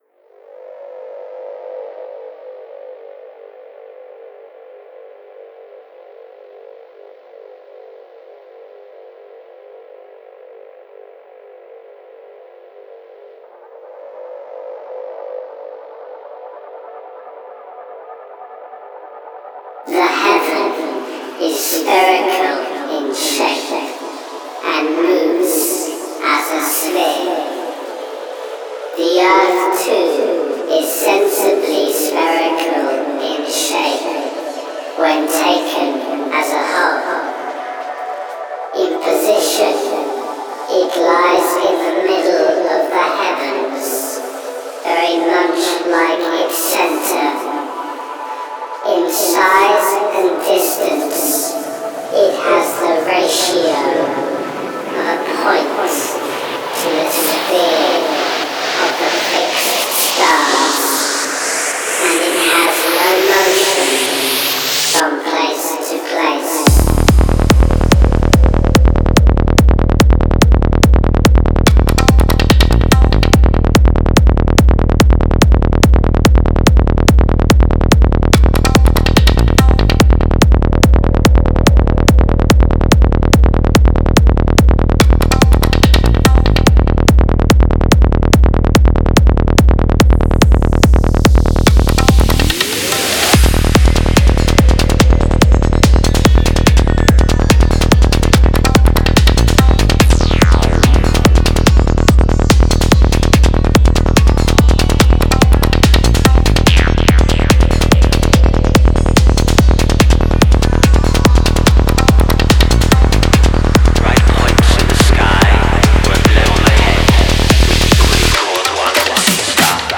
Genre: Goa, Psychedelic Trance.